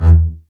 STR BASS M1W.wav